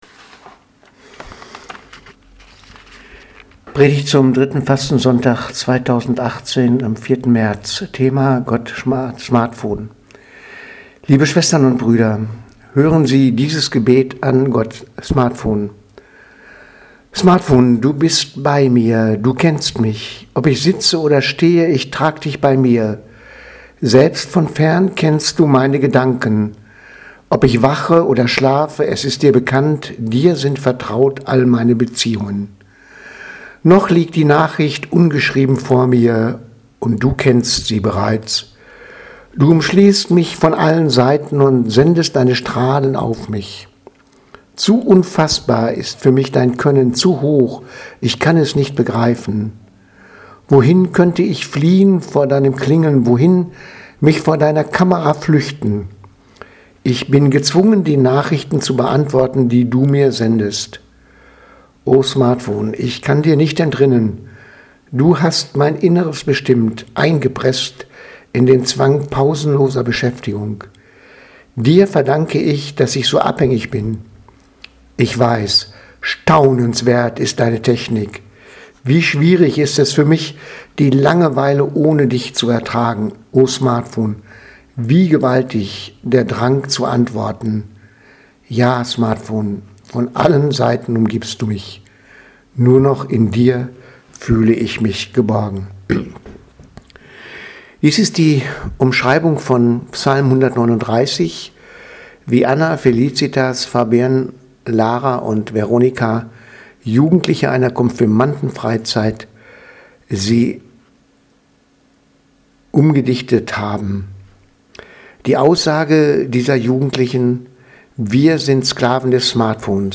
Predigt vom 4.3.2018 – Gott Smartphone